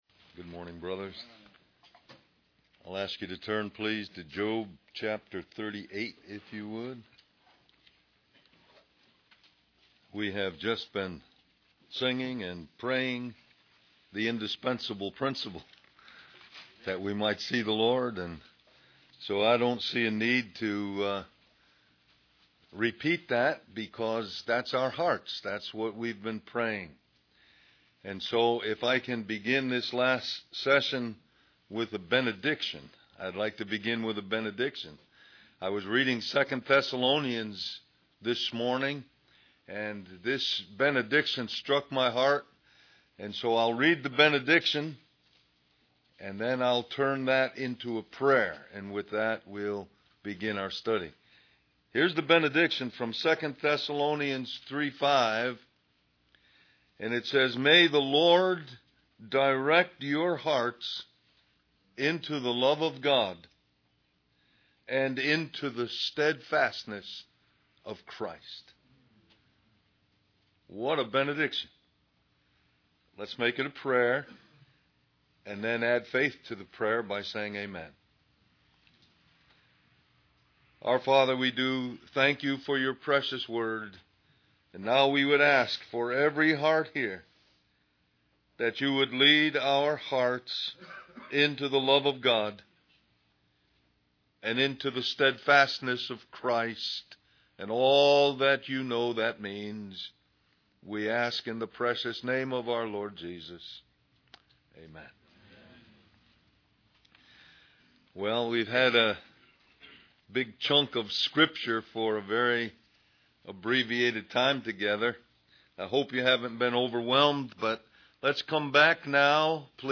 2006 Del-Mar-Va Men's Retreat Stream or download mp3 Summary In this section of Job, God Almighty reveals Himself as more than enough for Job.